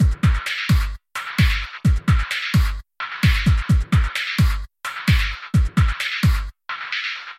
压缩钢琴2
标签： 130 bpm Breakbeat Loops Piano Loops 636.23 KB wav Key : Unknown